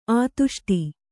♪ ātuṣṭi